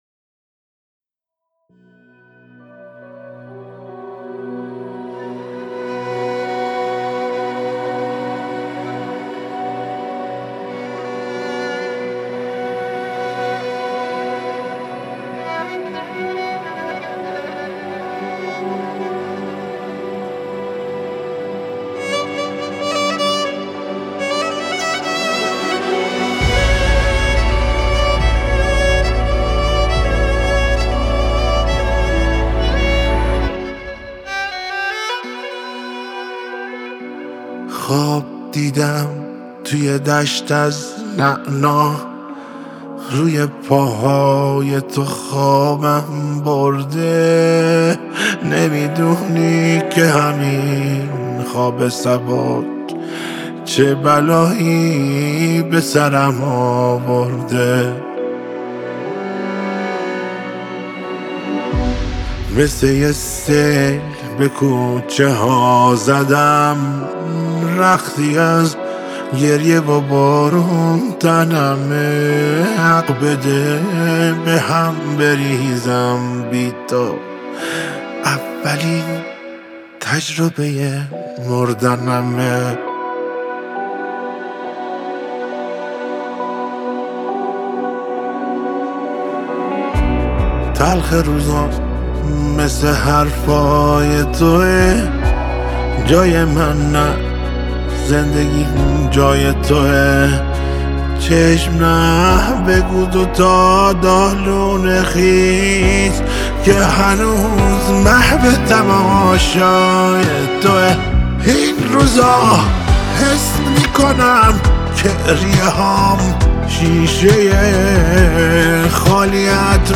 گیتار الکتریک
کمانچه